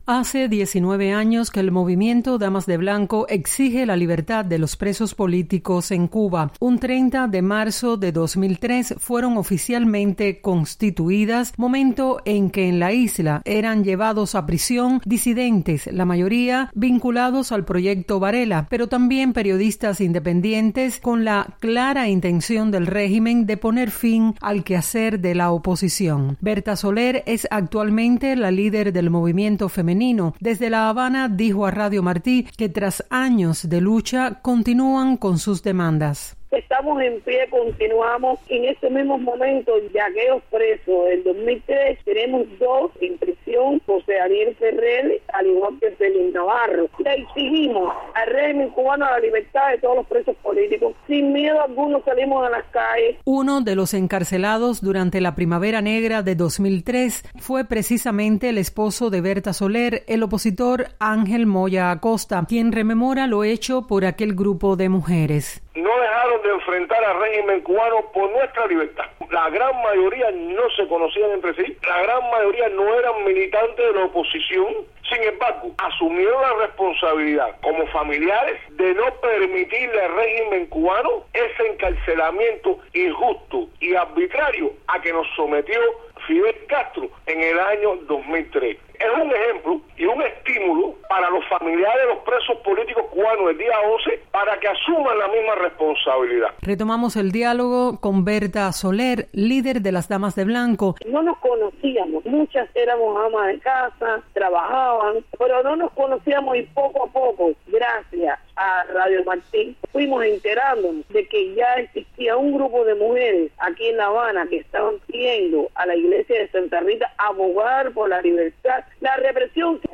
Líder de las Damas de Blanco habla de los inicios de la organización